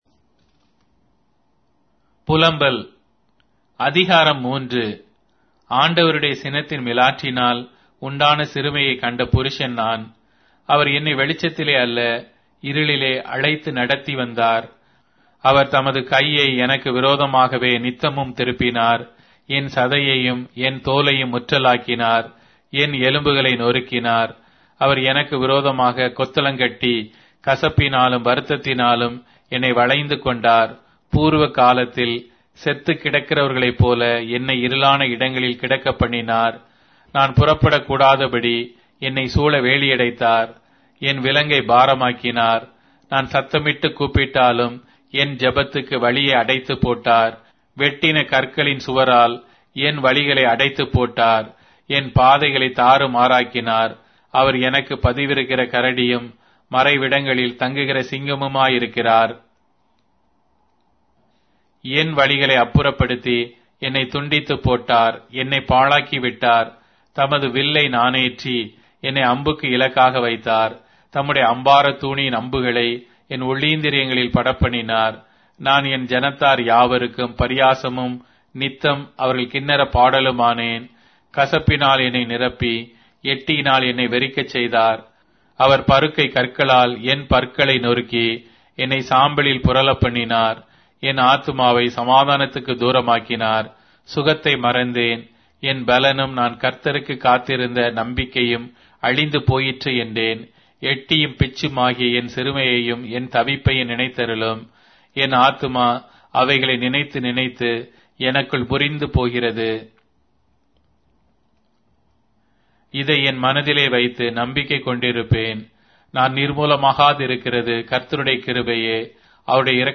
Tamil Audio Bible - Lamentations 1 in Gntbrp bible version